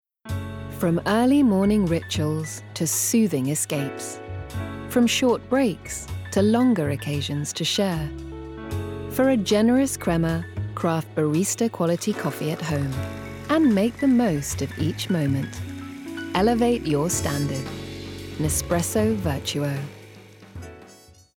30/40's Neutral/RP,
Elegant/Soft/Reassuring
• Commercial